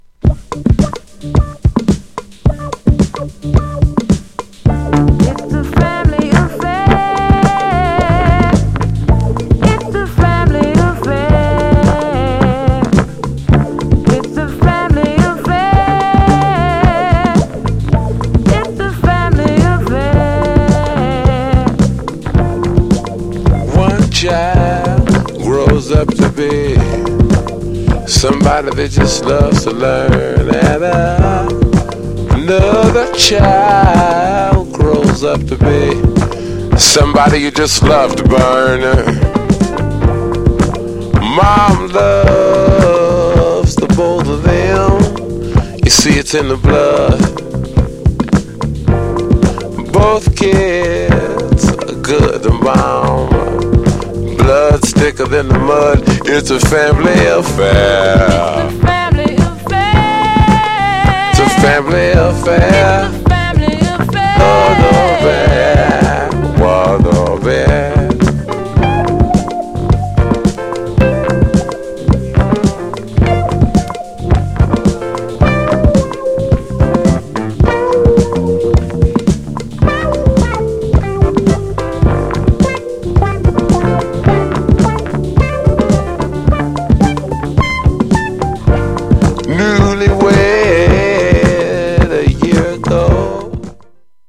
GENRE Dance Classic
BPM 106〜110BPM